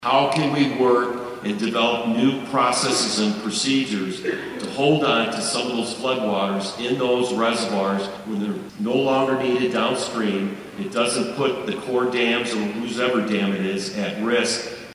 spoke to a gathering at Tuesday’s conference in Manhattan